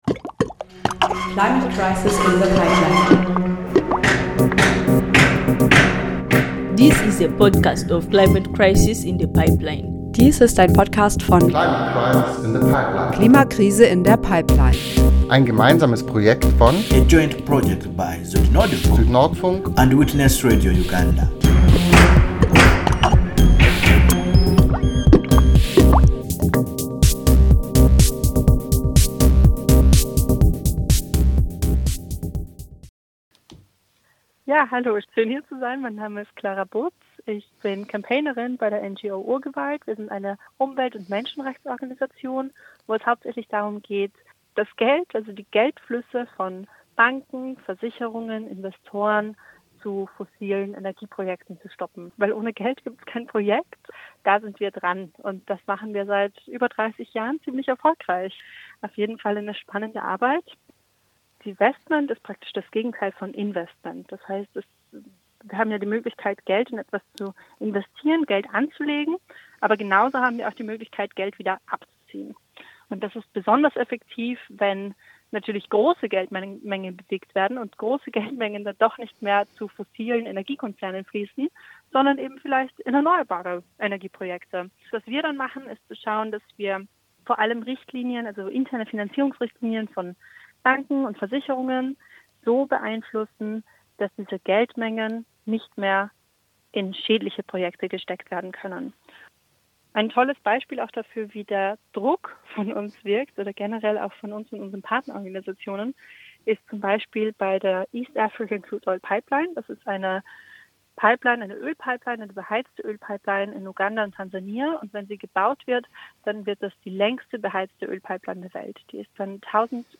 Interview urgewald mit Jingle.mp3